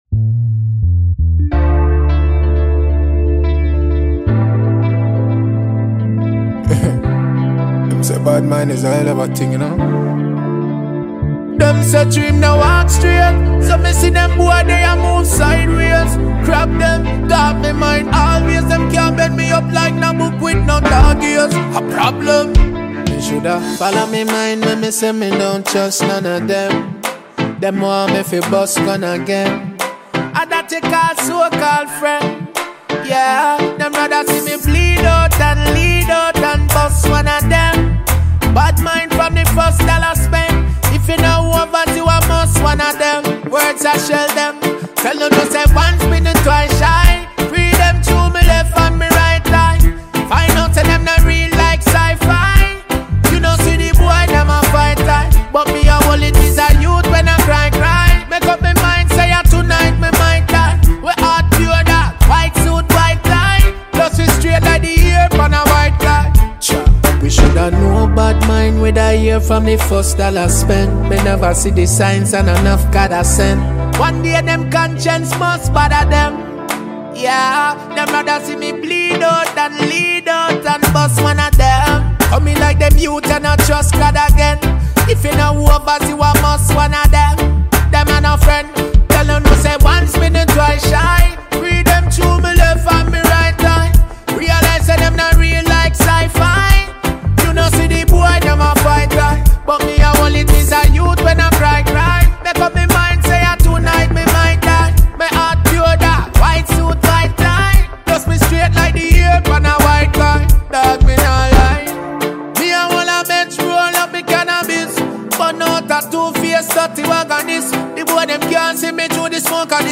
Dancehall
Jamaican dancehall artist